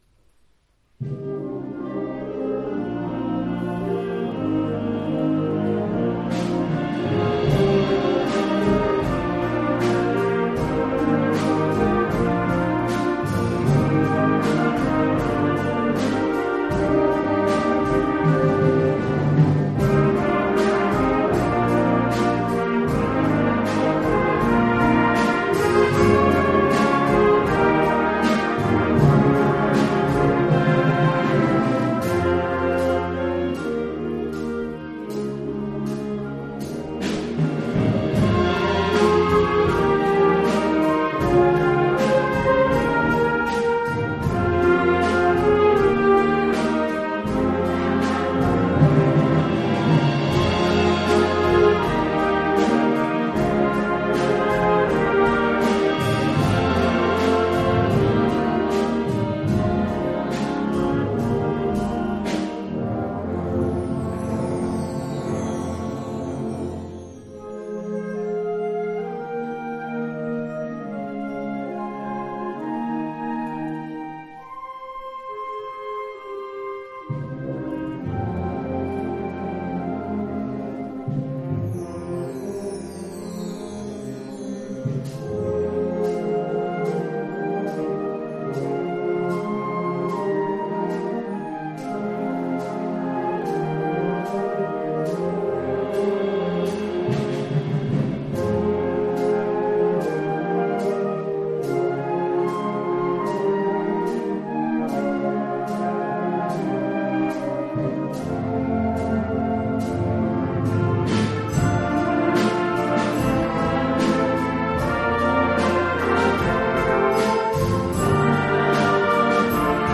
Harmonie (Orchestre d'harmonie)
Oeuvre pour orchestre d’harmonie.
Niveau : grade 2.